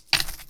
coinPurse.wav